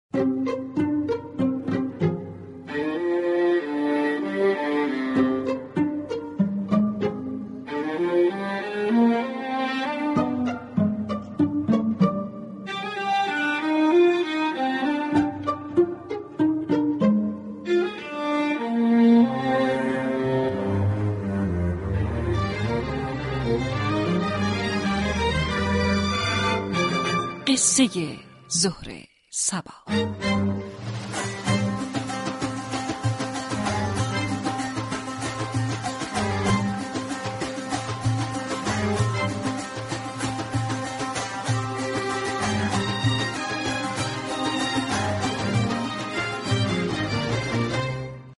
رادیو صبا در برنامه قصه های ظهر صبا برای مخاطبان داستان های كوتاه و شنیدنی روایت می كند.